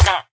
sounds / mob / villager / hit1.ogg
hit1.ogg